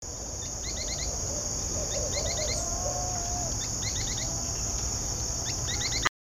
Pijuí Plomizo (Synallaxis spixi)
Nombre en inglés: Spix´s Spinetail
Fase de la vida: Adulto
Localidad o área protegida: Parque Natural Municipal Ribera Norte (San Isidro)
Condición: Silvestre
Certeza: Vocalización Grabada